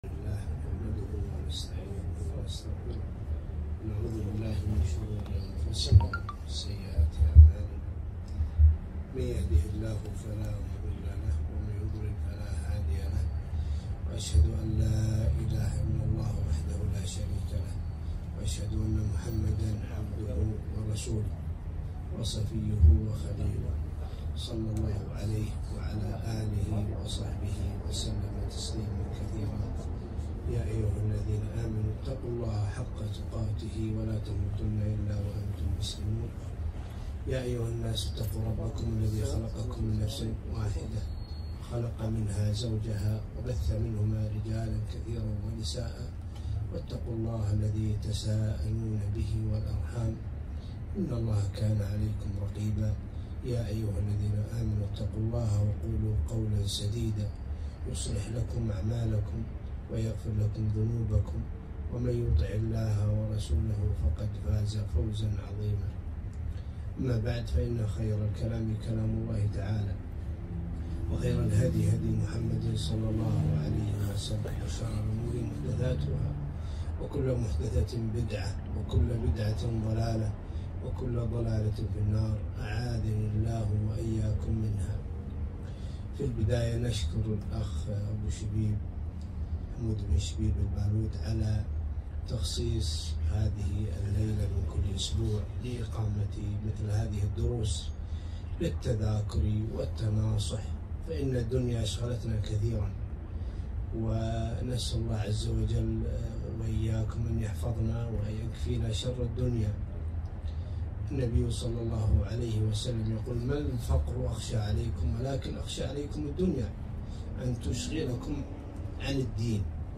محاضرة - فوائد من سورة العصر